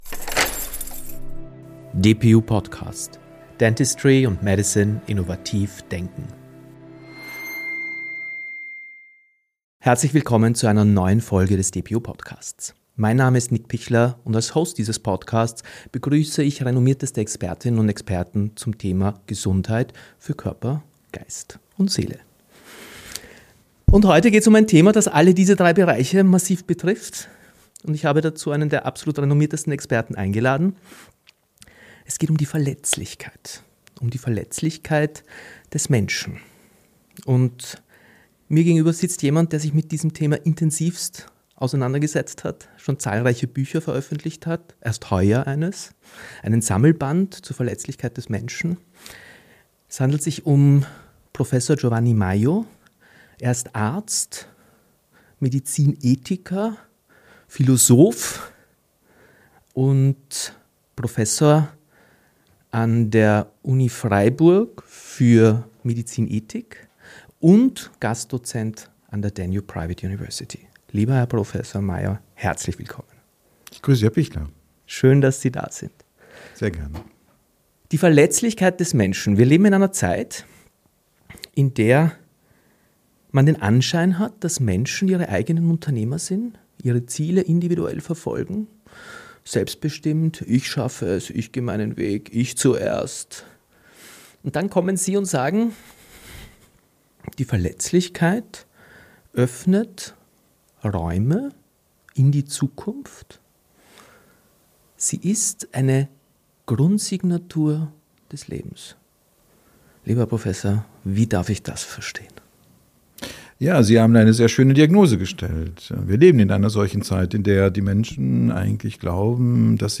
Ein tiefgreifendes Gespräch über die Zukunft der ärztlichen Rolle, den bewussten Umgang mit KI und darüber, was es heißt, als Mensch gesehen zu werden.